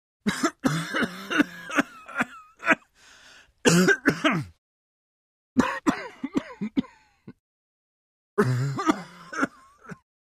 На этой странице собраны различные звуки кашля человека в высоком качестве.
Мужской кашель – 6 видов